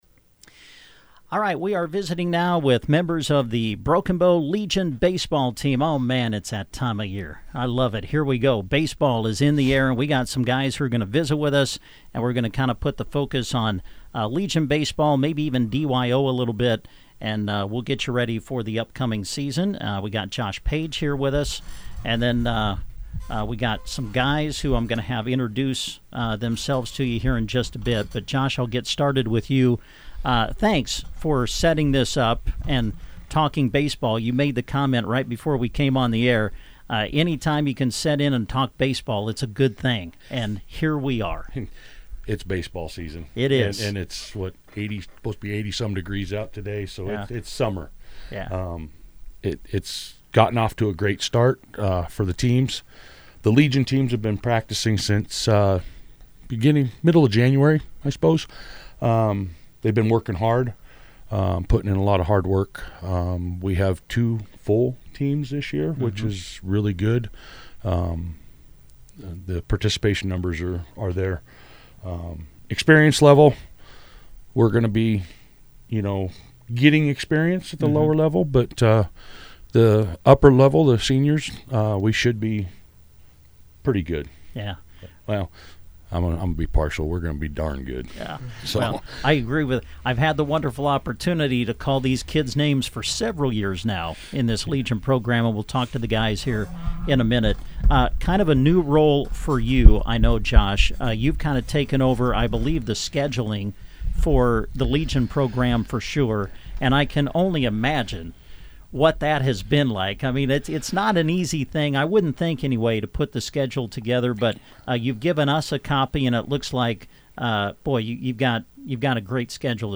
On Wednesday, members of the team stopped by the KBBN studio to visit about the upcoming season.
LEGION-BASEBALL-INTERVIEW_.mp3